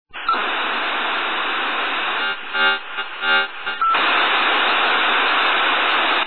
modem2.mp3